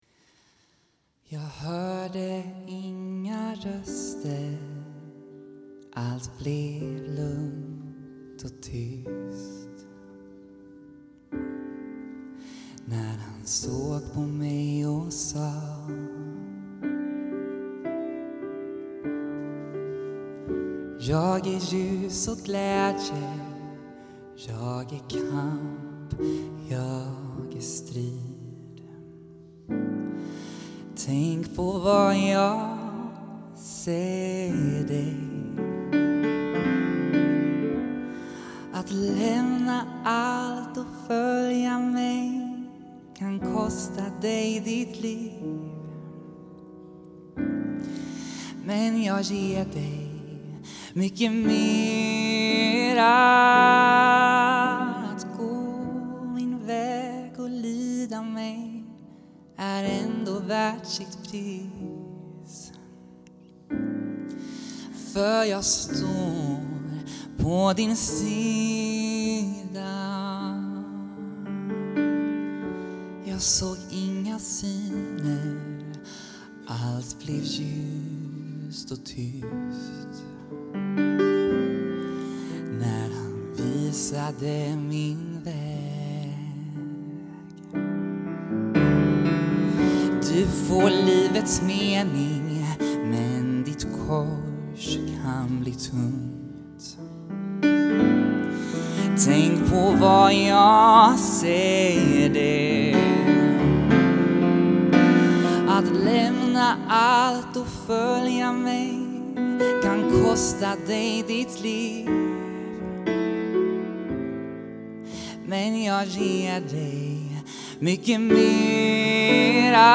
Missionsgudstjänst